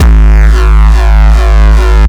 Desecrated bass hit 05.wav